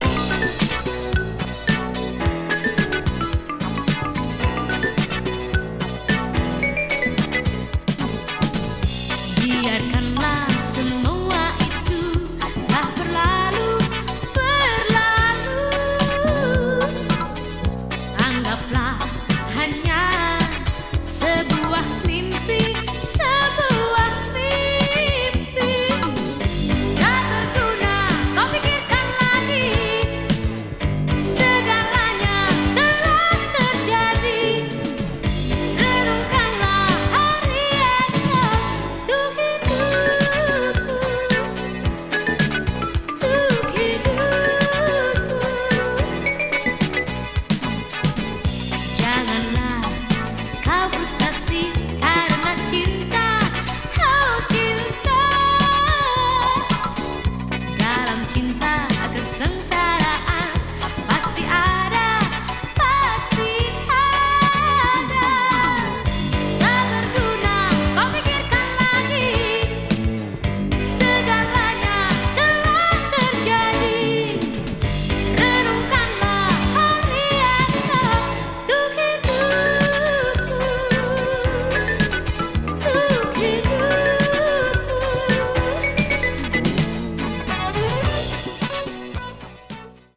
media : EX/EX(some slightly noise.)
keroncong   obscure dance   south east asia   world music